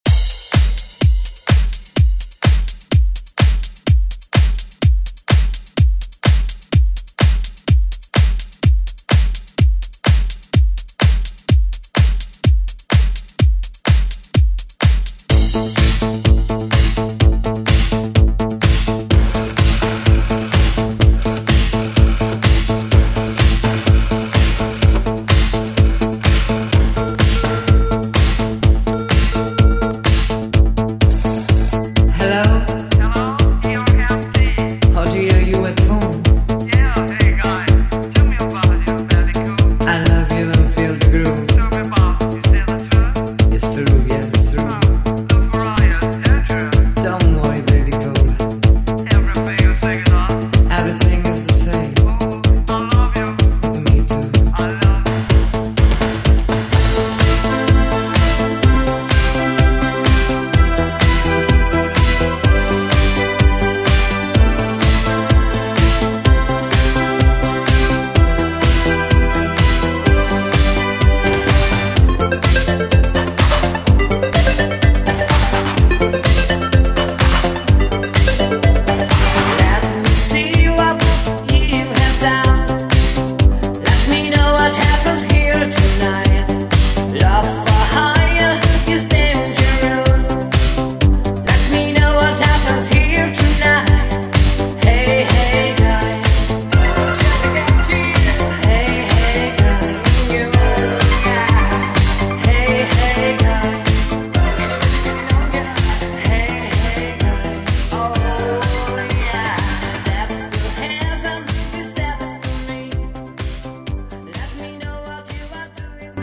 Genre: LATIN
Clean BPM: 122 Time